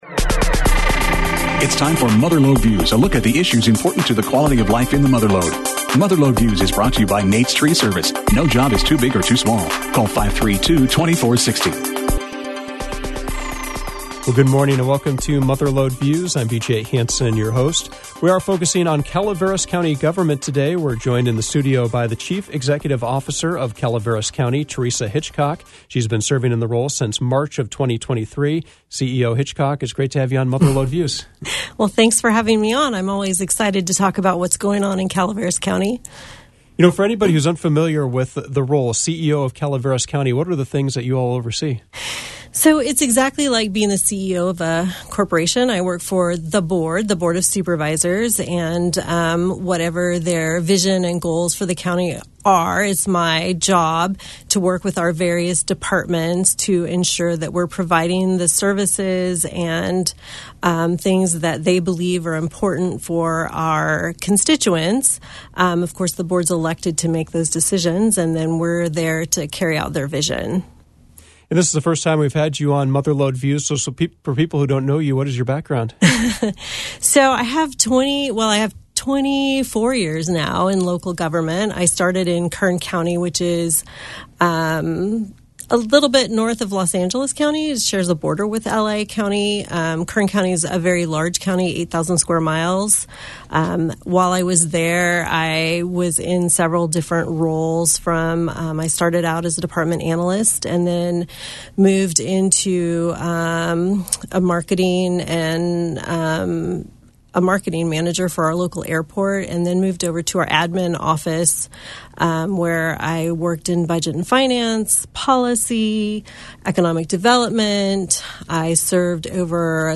Mother Lode Views featured a conversation with Calaveras County Chief Executive Officer Teresa Hitchcock. She spoke about revenue trends, road projects, infrastructure improvements, and other county government happenings.